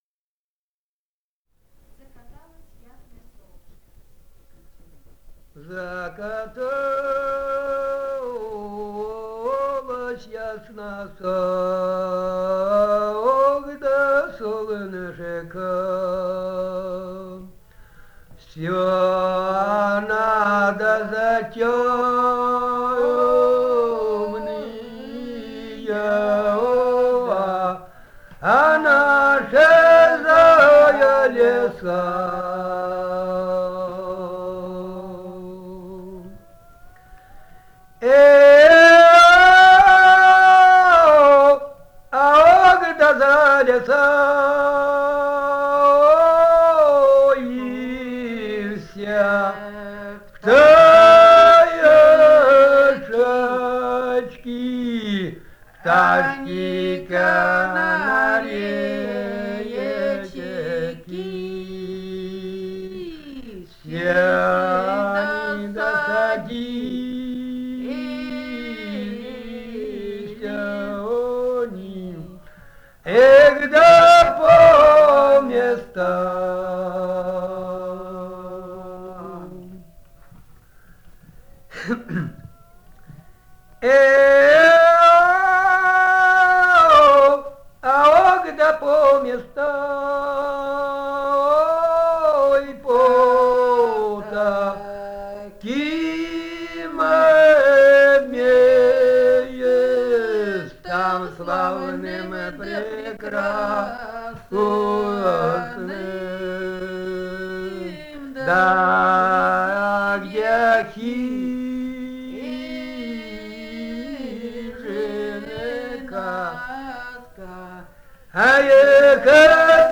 Этномузыкологические исследования и полевые материалы
Алтайский край, с. Михайловка Усть-Калманского района, 1967 г. И1001-12